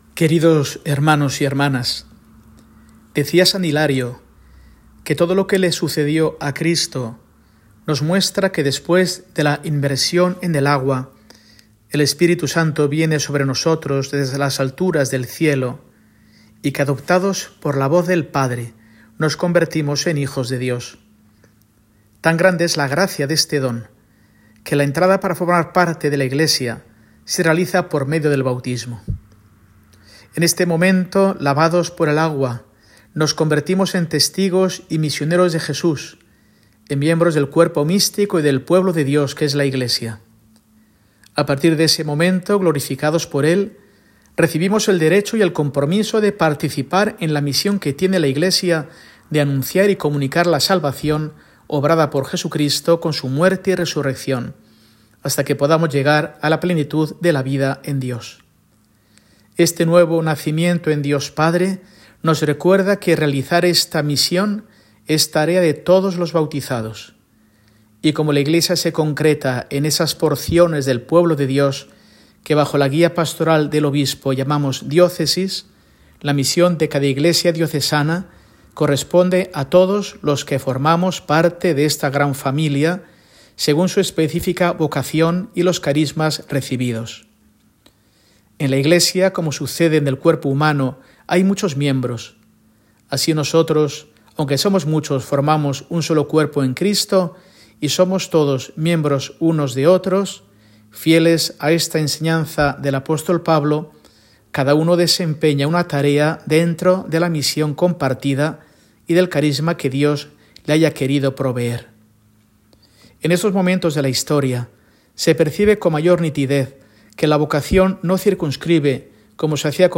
Mensaje semanal de Mons. Mario Iceta Gavicagogeascoa, arzobispo de Burgos, para el domingo, 10 de noviembre de 2024, Día de la Iglesia Diocesana